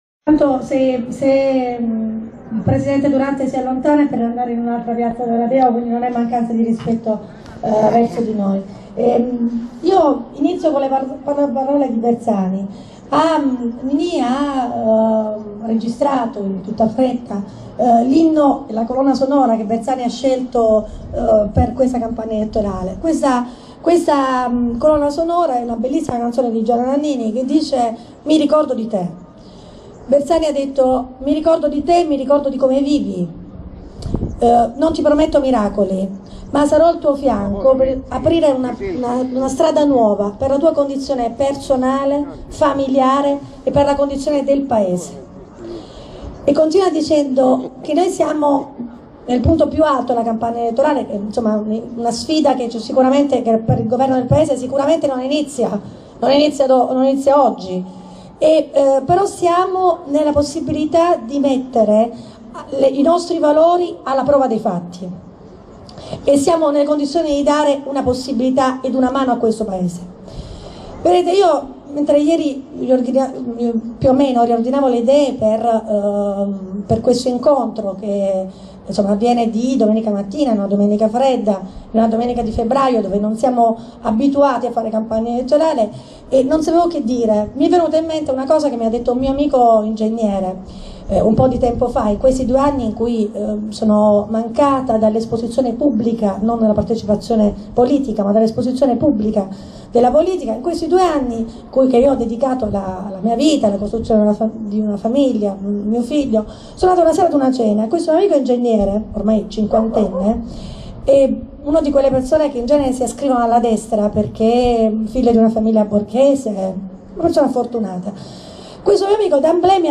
(In allegato l'audio del suo comizio) Ascolta da qui il comizio di Sandra Antonica Tweet Lunedì, 4 Febbraio, 2013 - 00:07